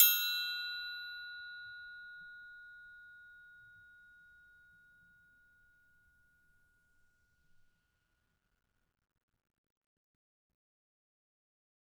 Triangle3-Hit_v2_rr1_Sum.wav